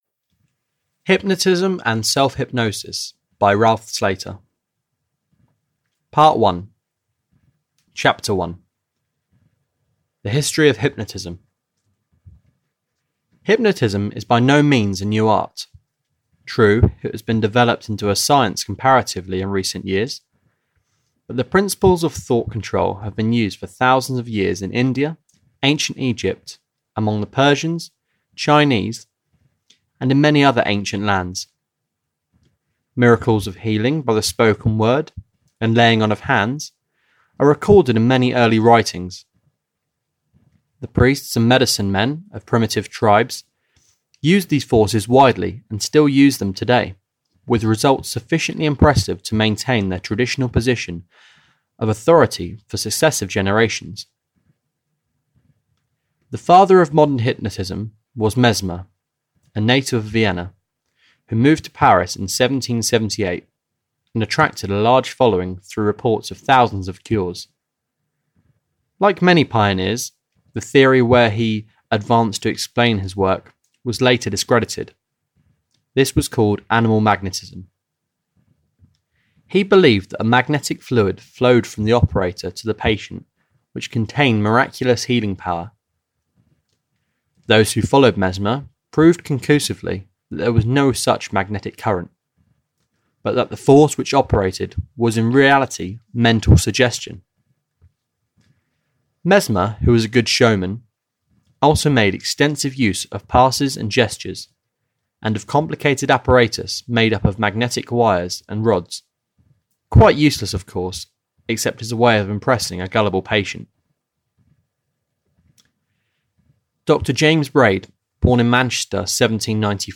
Audio knihaHypnotism and Self Practice (EN)
Ukázka z knihy